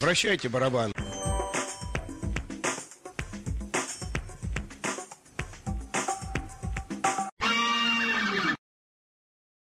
Такое же, только еще играет музыка после этих слов